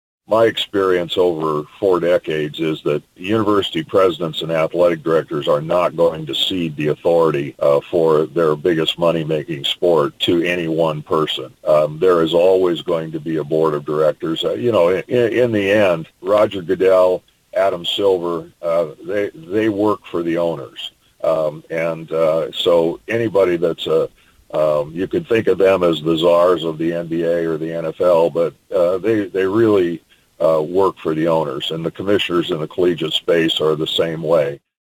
Bowlsby on ESPN Radio yesterday.